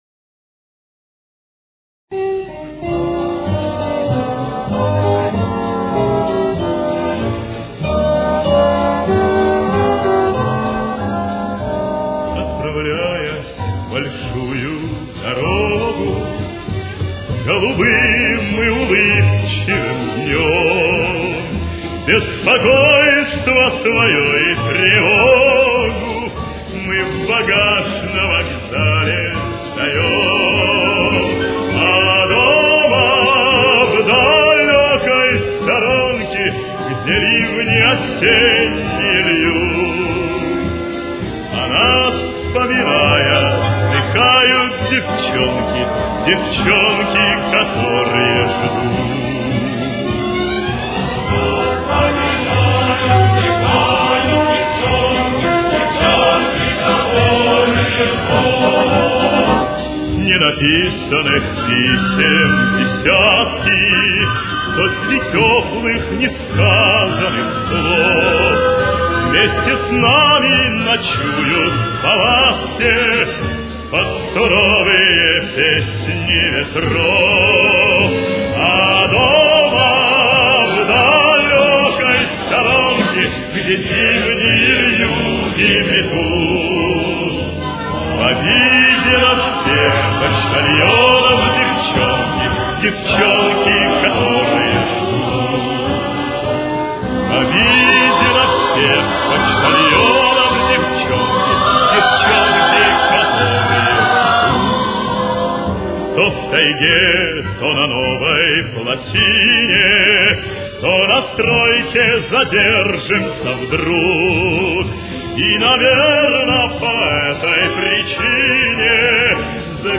Темп: 106.